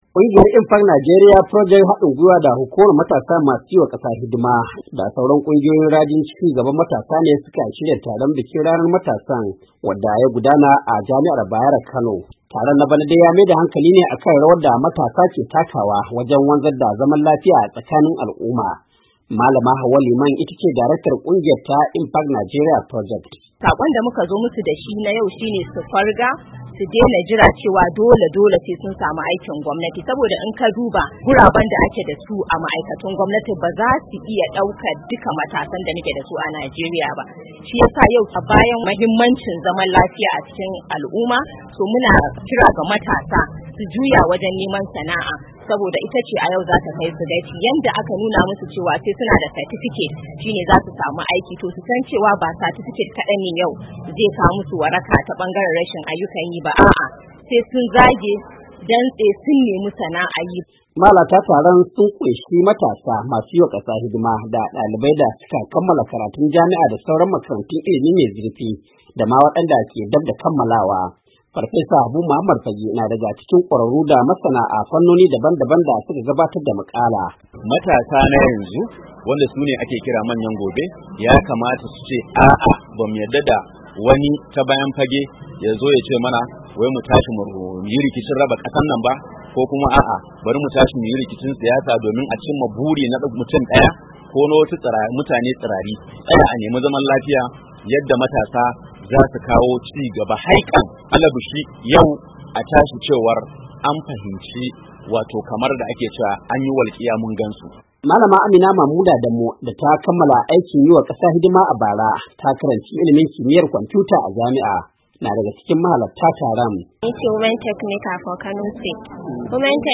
An yi wannan kira ne yayin da aka yi bikin ranar tunawa da matasa a karshen makon da ya gabata, wanda ya gudana a Jami’ar Bayero da ke Kano, domin bin sahun sauran kasashen duniya da ke bikin wannan rana ta matasa.